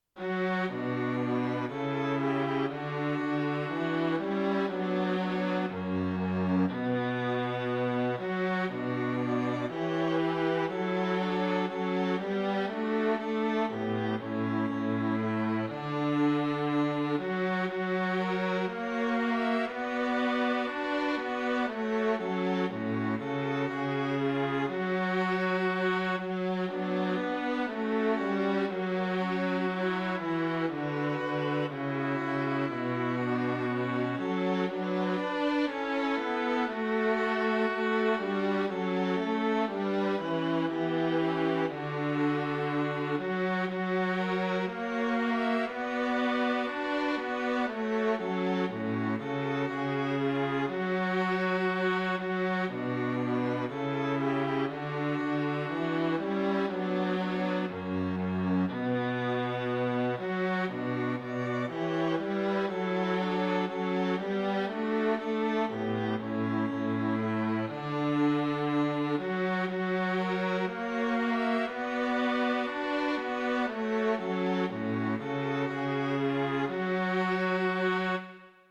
Klik på linket 'Alt øve', 'Tenor øve' etc. for at høre korsatsen med fremhævet understemme.
SAB korsats bygget over egen melodi 2008
Baryton øve